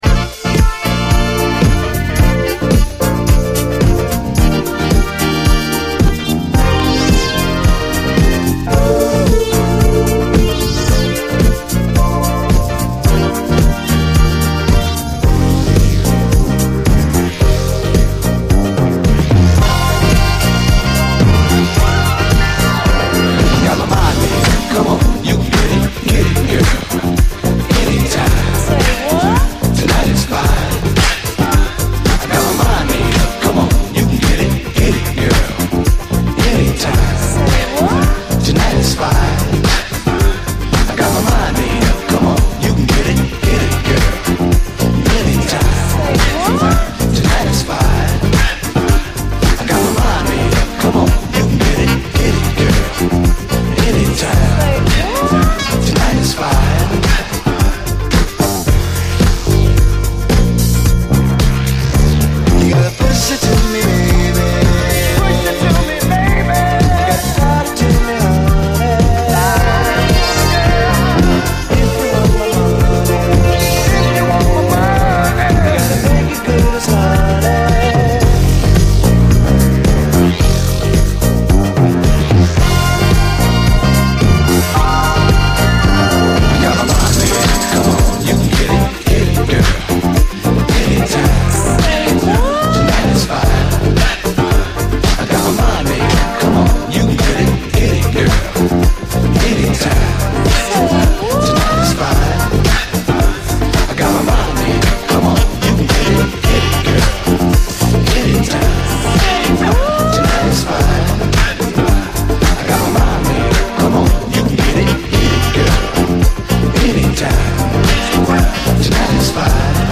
SOUL, 70's～ SOUL, DISCO, 7INCH
おいしいトコをギュッと凝縮した７インチ・エディットであることが重要！